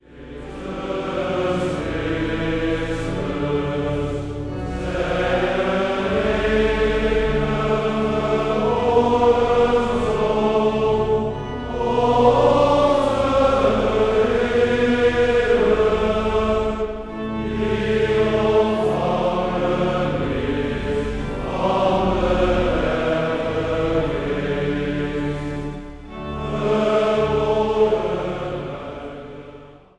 orgel
Zang | Mannenzang